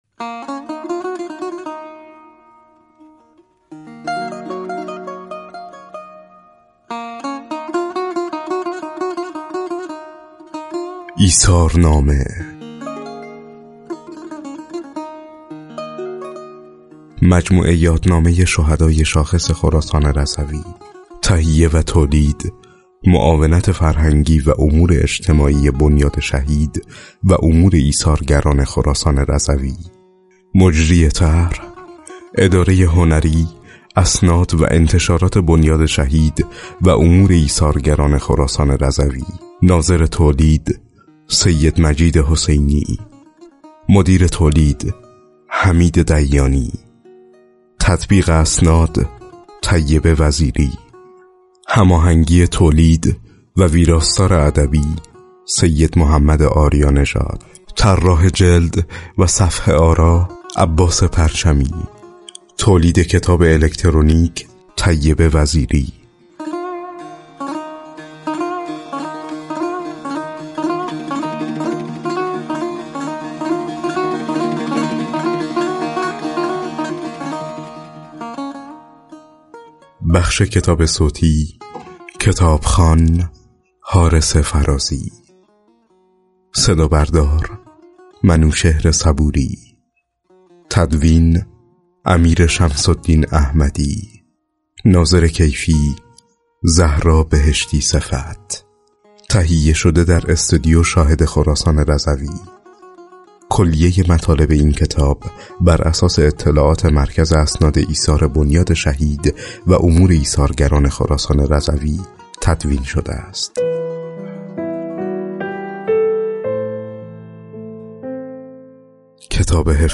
لینک دانلود کتاب صوتی لینک دانلود پوسترها مرورگر شما قابلیت نمایش کتاب الکترونیکی را پشتیبانی نمی کند.